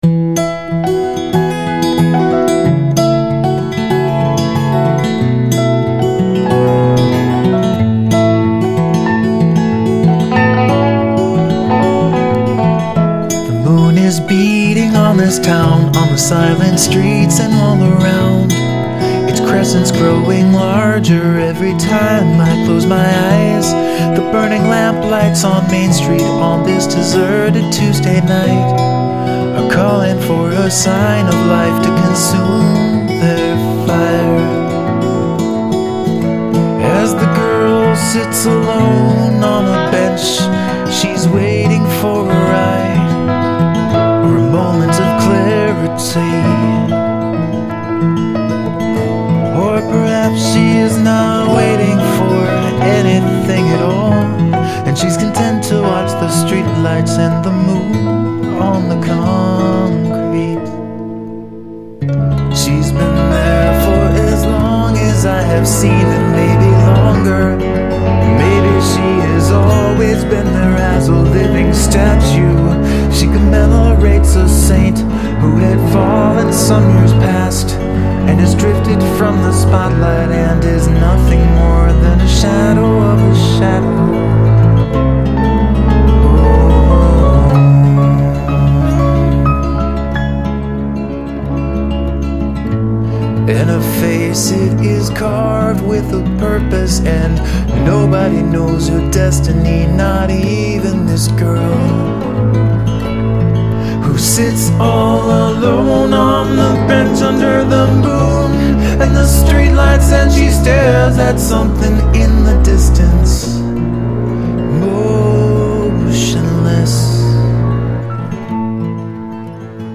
My experimentation with "chamber folk".
Oh, and a vibrophone at the end.
Smooth yet slightly moody.